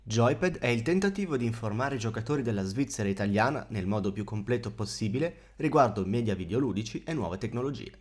Qua sotto trovate un comparativo registrato con tre microfoni: il microfono integrato della webcam Logitech Brio 4K, il microfono delle cuffie LogitechG Pro X e Razer Seiren.
I nostri sample in pratica sottolineano l’ovvio: il microfono della webcam è a malapena sufficiente, quello delle cuffie va bene per Discord mentre Seiren è meglio sotto ogni aspetto: la voce è più calda, precisa, senza distorsioni.